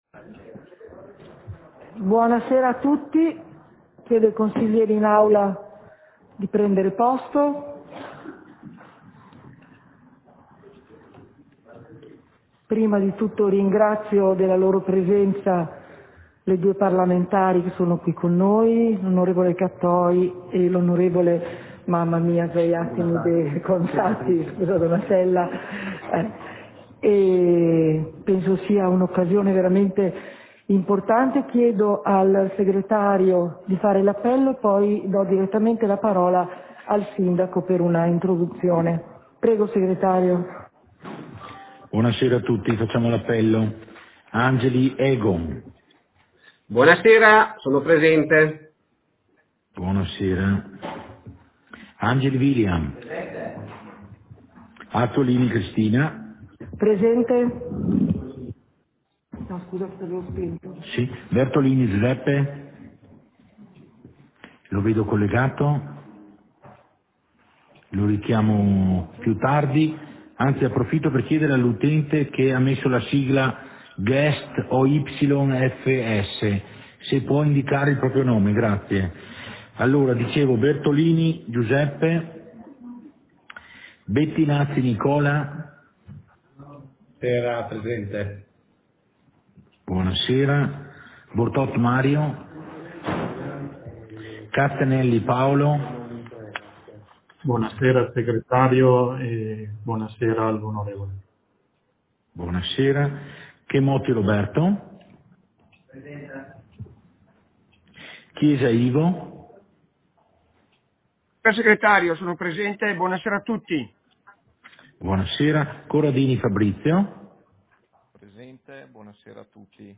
Seduta del consiglio comunale - 17.01.2022